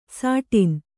♪ sāṭin